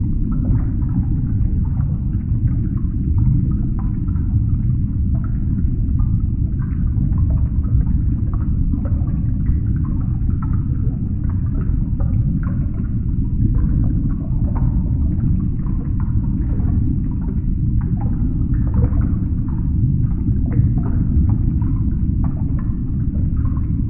underwater_sea_diving_bubbles_loop_02.wav